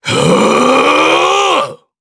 DarkKasel-Vox_Casting4_jp.wav